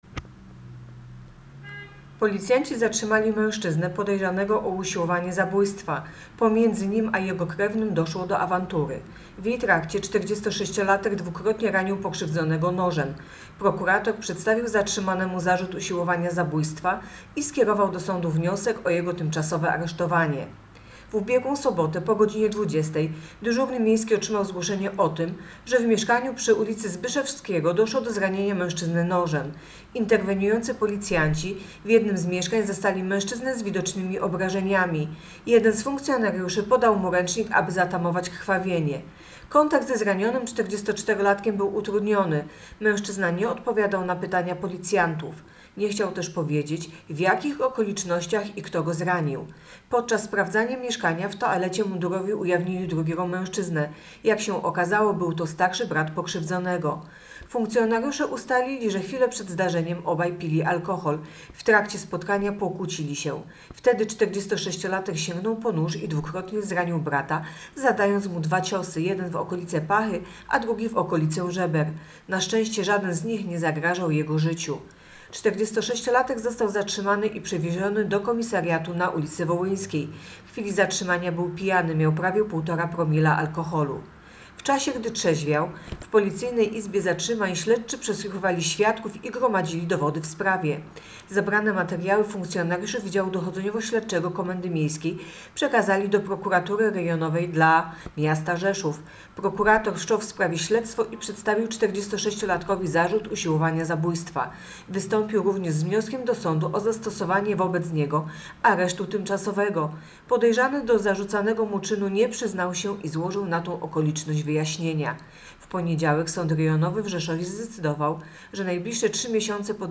Opis nagrania: nagranie informacji pt. Areszt tymczasowy dla 46-latka za usiłowanie zabójstwa brata.